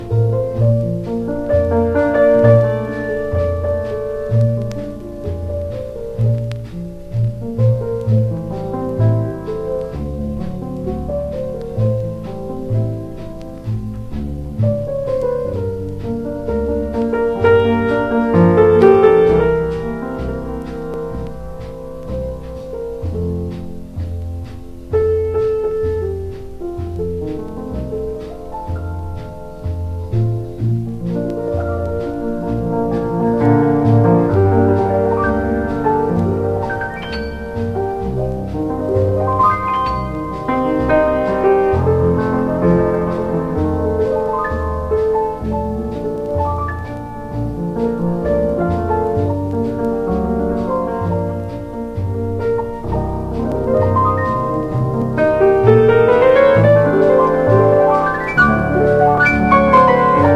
SOUL / SOUL / 70'S～ / LATIN SOUL / FUNK
メロウなヴォーカルのグルーヴィー・ラテン・ファンク